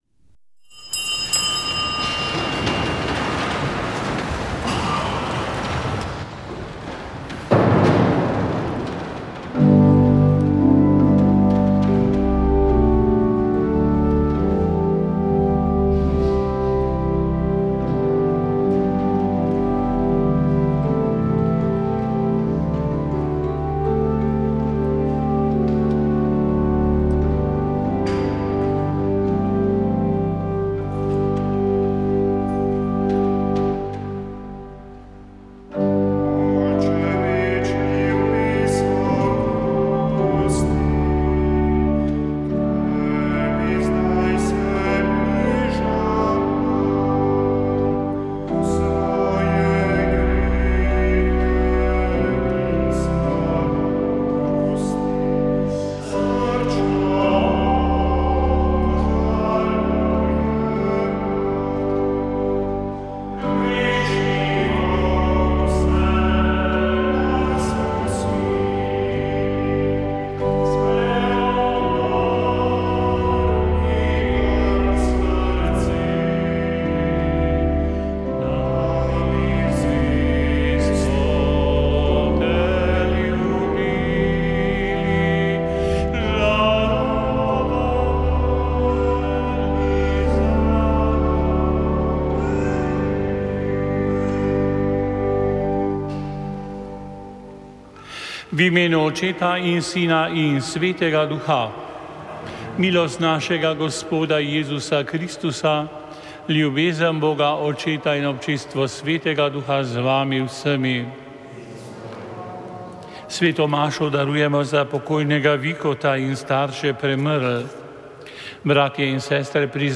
Sveta maša
Posnetek svete maše iz mariborske stolnice
Na 3. velikonočno nedeljo smo prenašali posnetek svete maše iz mariborske stolnice, ki jo je daroval nadškof msgr. Alojzij Cvikl.